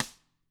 Index of /90_sSampleCDs/ILIO - Double Platinum Drums 1/CD4/Partition B/WFL SNRD